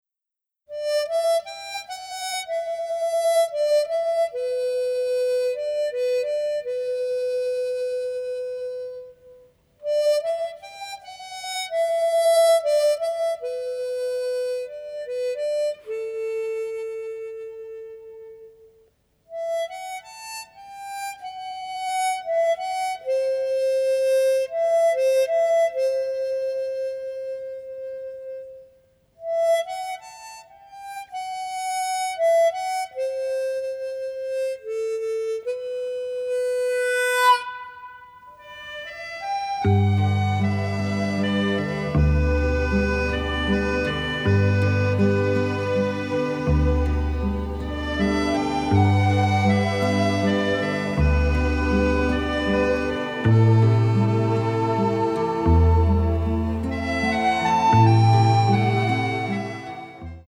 soundtrack album
builds upon classic Italian songs
original stereo session elements